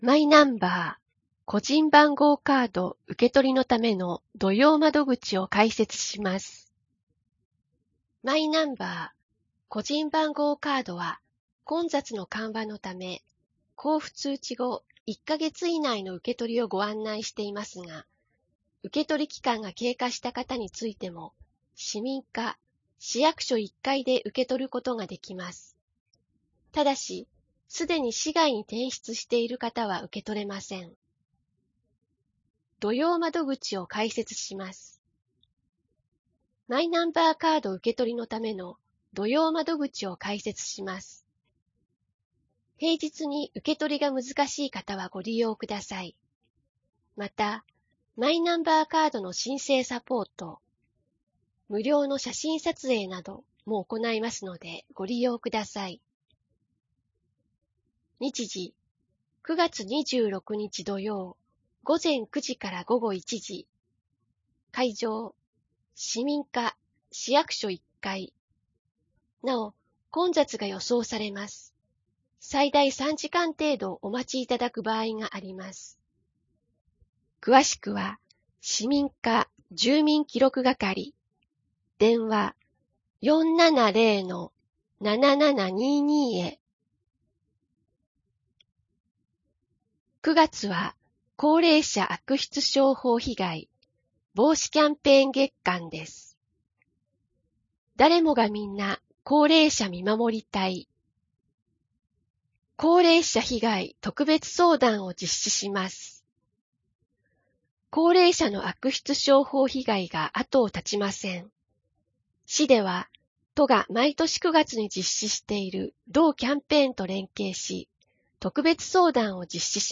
声の広報（令和2年9月1日号）